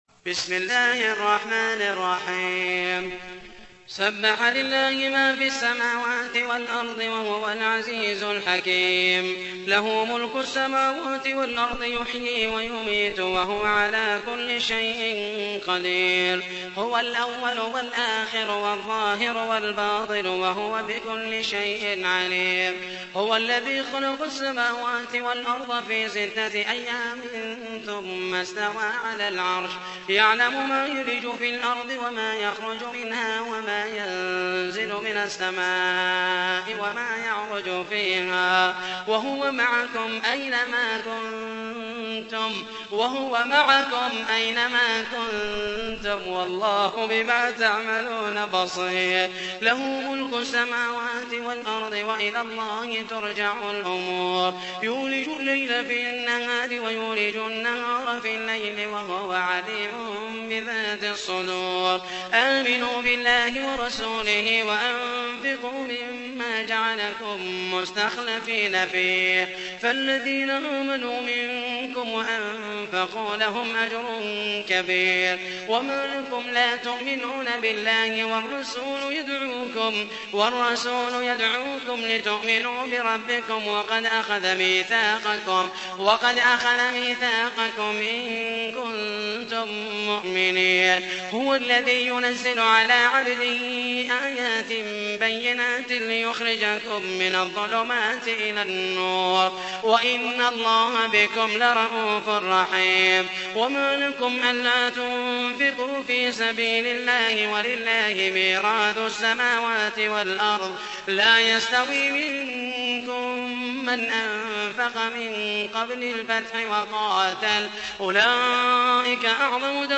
تحميل : 57. سورة الحديد / القارئ محمد المحيسني / القرآن الكريم / موقع يا حسين